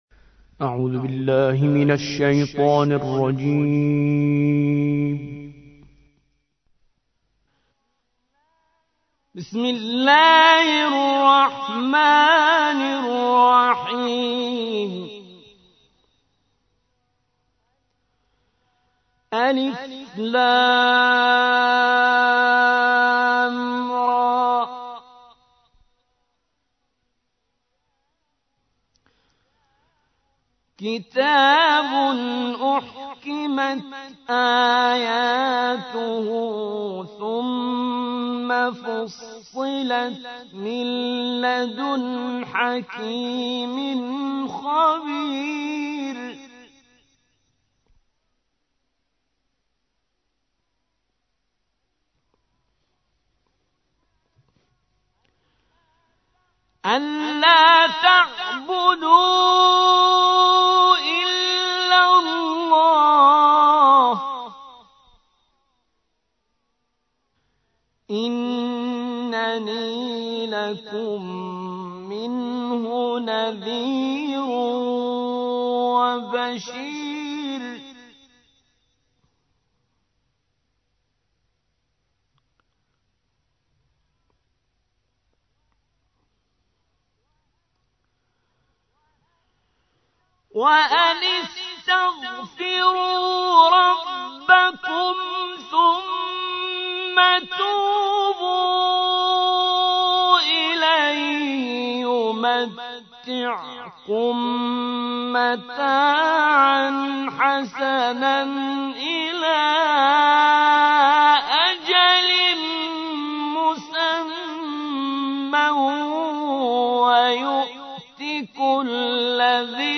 11. سورة هود / القارئ